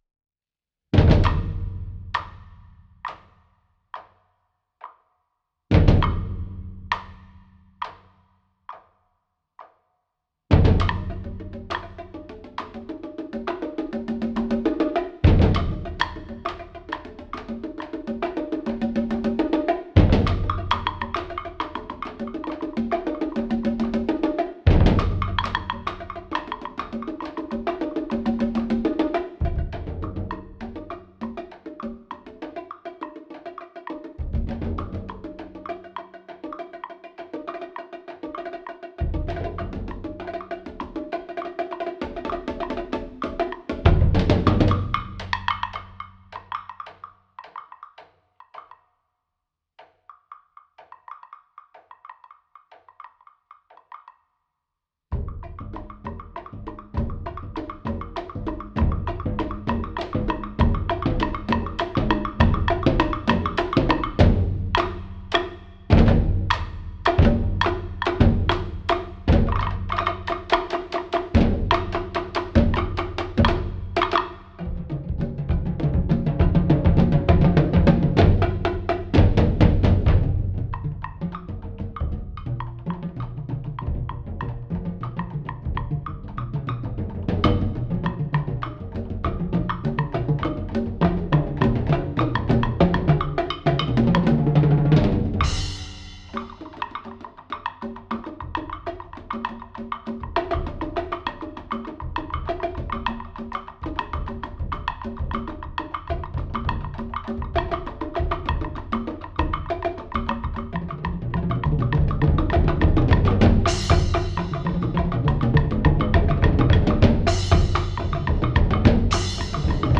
Voicing: Percussion Quartet